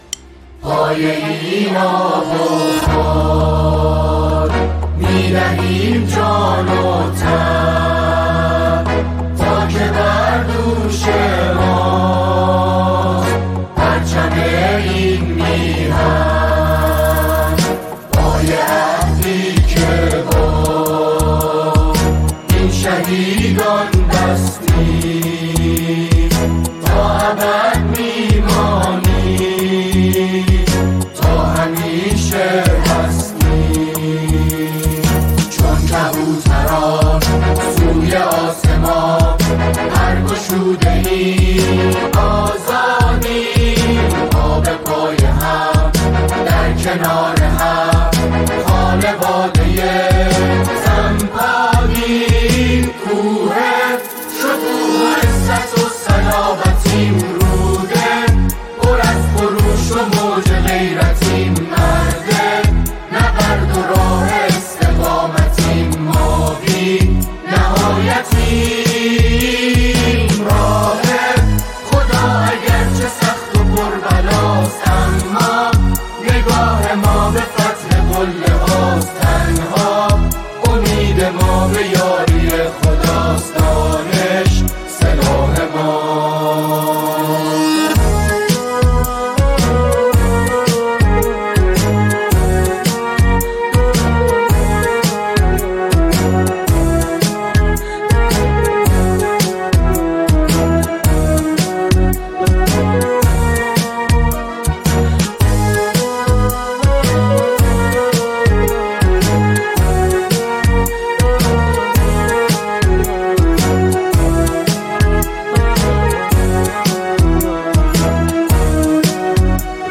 متن و آهنگ سرود سمپاد